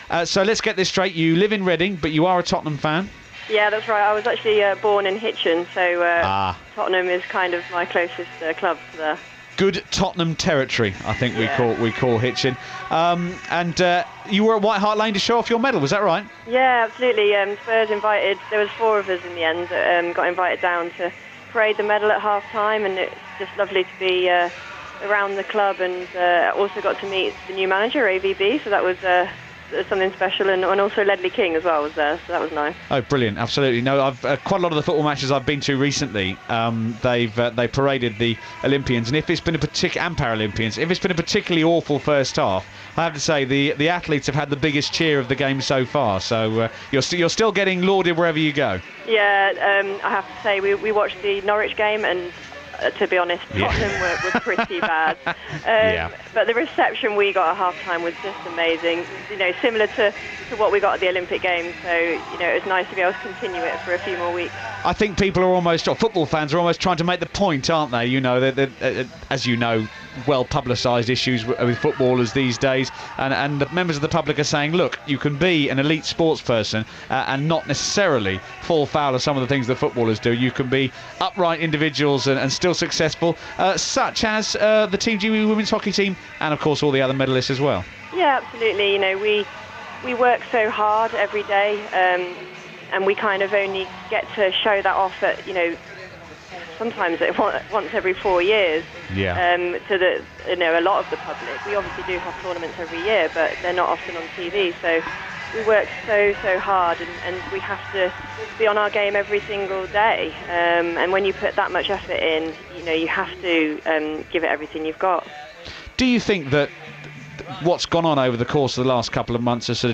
Team GB's women's hockey bronze medallist Helen Richardson talks to BBC London 94.9